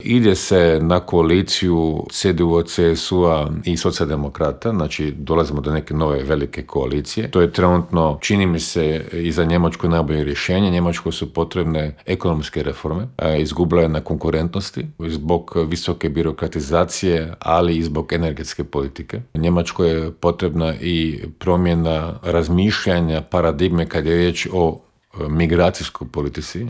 ZAGREB - Bivši ministar vanjskih poslova Miro Kovač gostovao je u Intervjuu Media servisa u kojem se, među ostalim, osvrnuo na rezolucije o Ukrajini koju je Opća skupština Ujedinjenih naroda jučer donijela povodom treće obljetnice rata u toj zemlji.